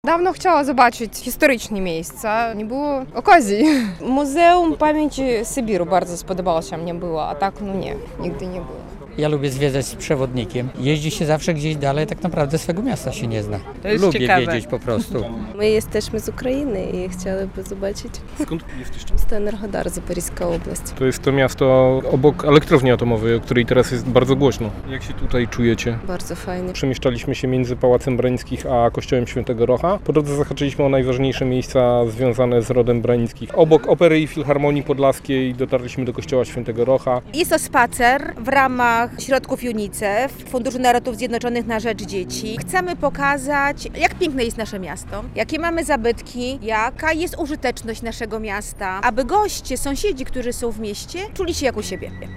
W półtora godzinnej wędrówce uczestniczyło kilkadziesiąt osób, które wyruszyły sprzed Pałacu Branickich.